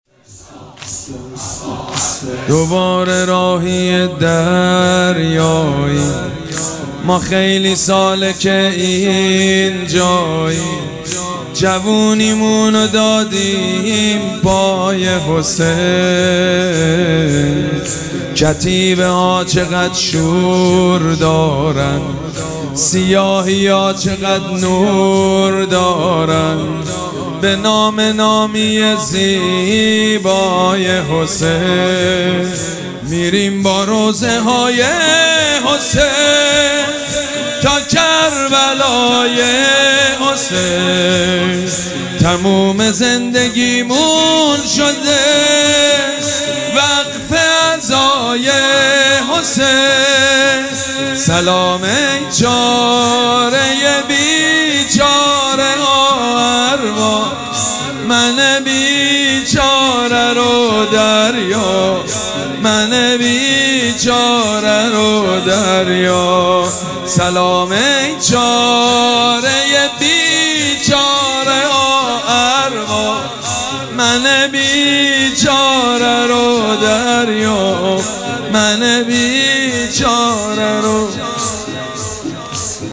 شب اول مراسم عزاداری اربعین حسینی ۱۴۴۷
حاج سید مجید بنی فاطمه
مراسم عزاداری اربعین حسینی